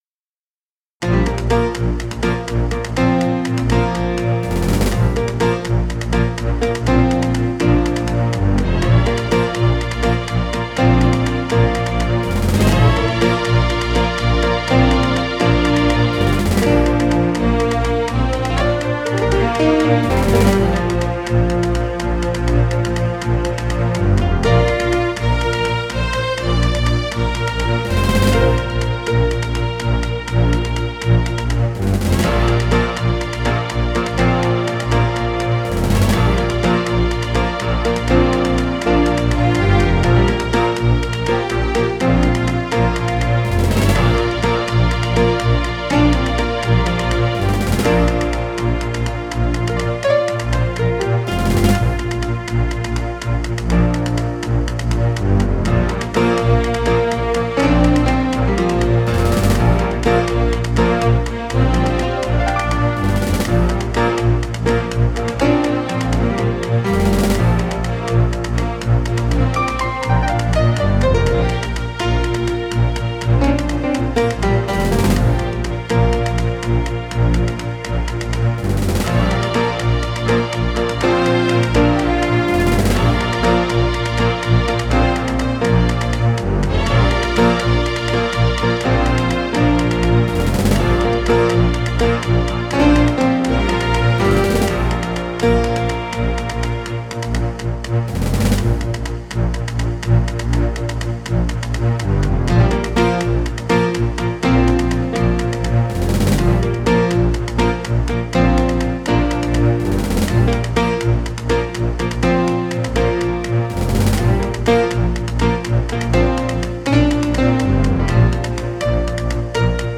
recorded from a Roland Sound Canvas SC-55mkII